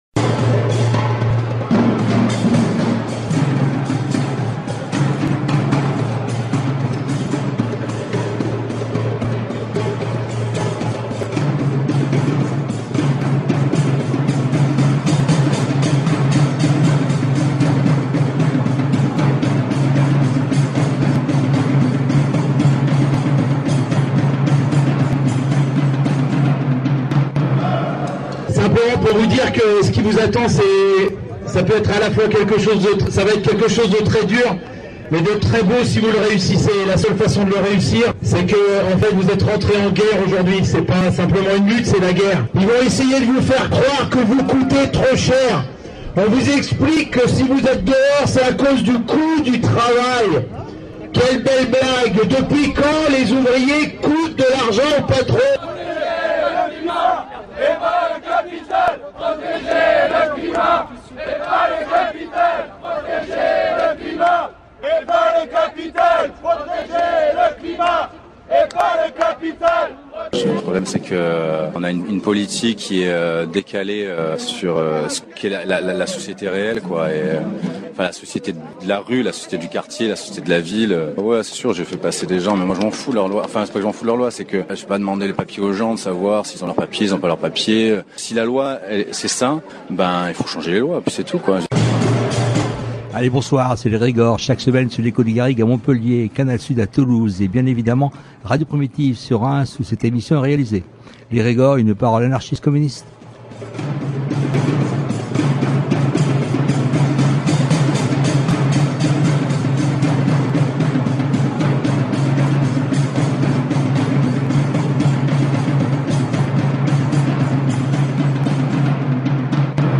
Dans l’émission de ce jour, nous vous proposons l’écoute de deux table-rondes, qui se tenait au Cirque électrique, le 24 mai dernier avec comme titre Guerre et répression : le business lucratif de la mort.